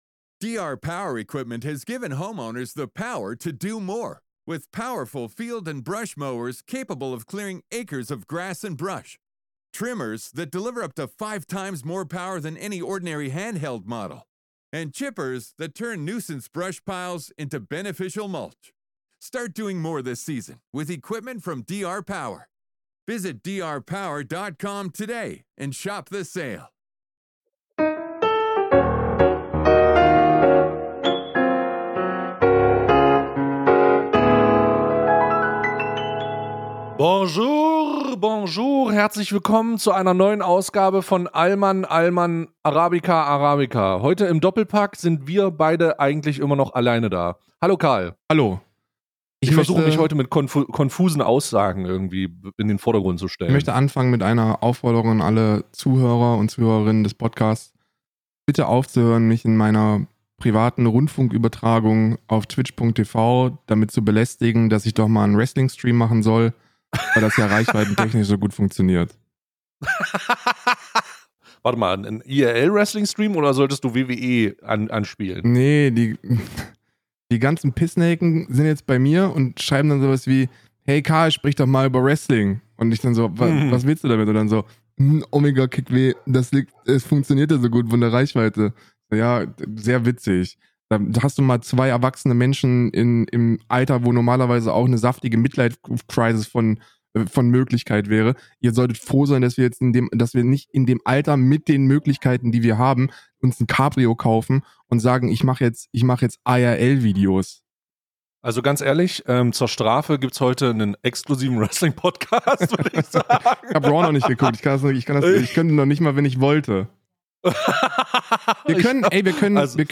zwei hochgewichtige Urdeutsche mit ausgeprägter suburbaner Sprachkultur pressen erfrischend polarisierende Meinungen satirisch in perfekt maßgeschneiderte Siebträger